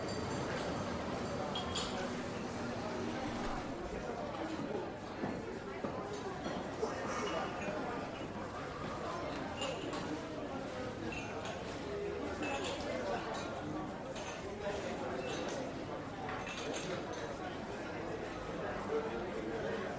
Bar Ambience
Bar Ambience is a free ambient sound effect available for download in MP3 format.
449_bar_ambience.mp3